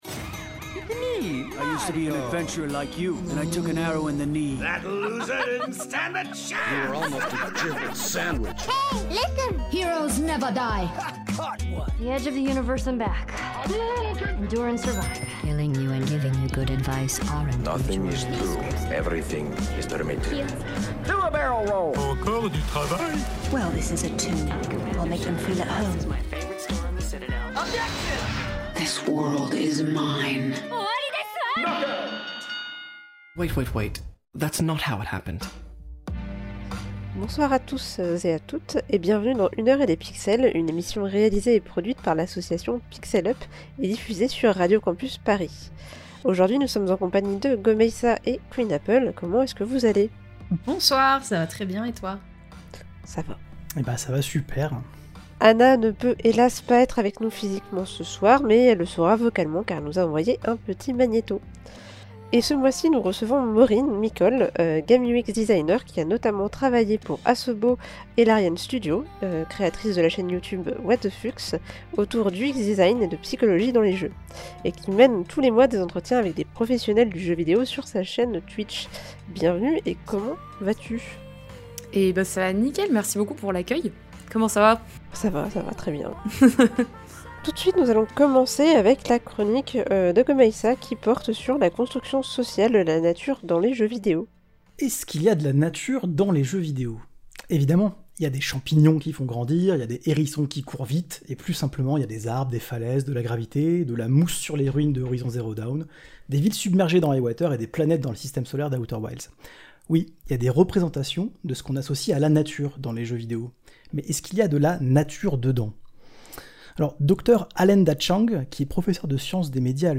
Émission diffusée le 29 novembre 2025 sur Radio Campus Paris.
Type Magazine Culture